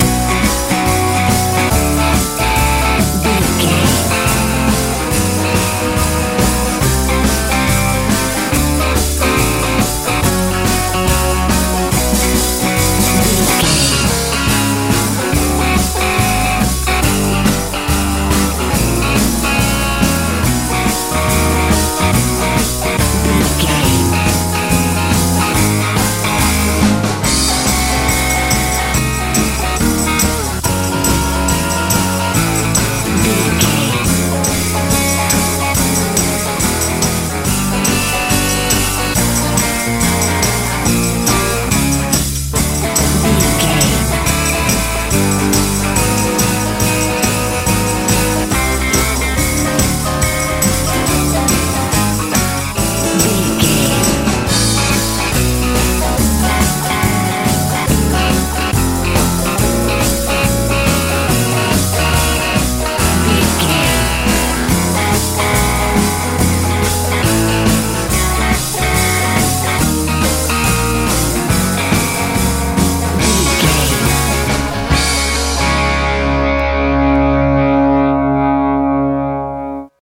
rock n roll feel
Ionian/Major
driving
groovy
acoustic guitar
electric guitar
bass guitar
drums
sweet
joyful
hopeful